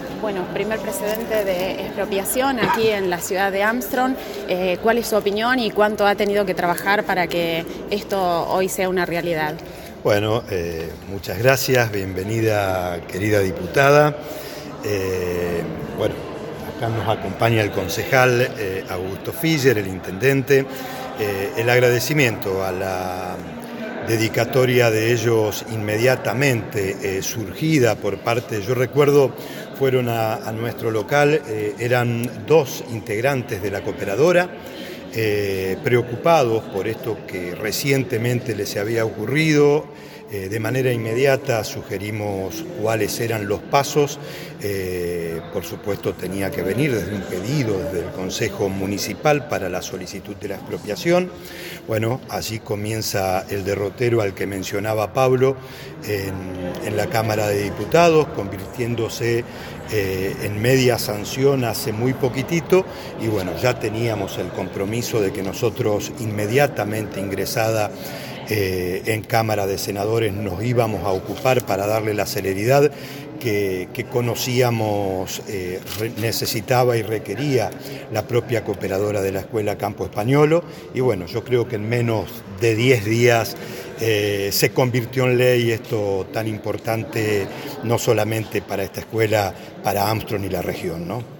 Convocada la prensa a la Escuela Campo Spagnolo tuvimos la posibilidad de hablar con autoridades Provinciales, Locales. la noticia es que la Escuela Campo Spagnolo podrá disponer del inmueble recuperado y las instalaciones en donde se encuentra.
Entrevista Senador Guillermo Cornaglia